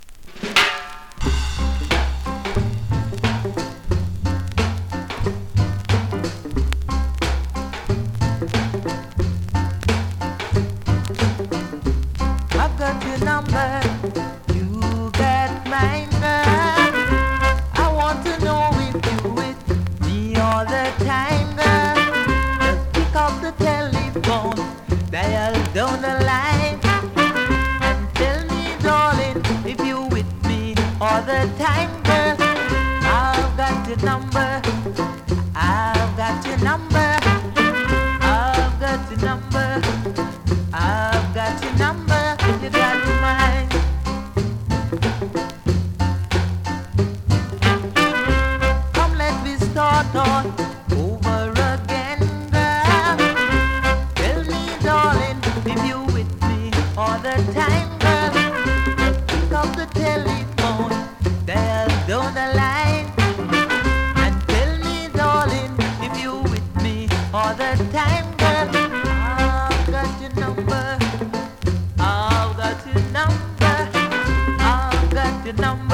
両面とも数回針ブレますがプレイOKです)   コメントレアラベル!!レアROCKSTEADY!!
スリキズ、ノイズ比較的少なめで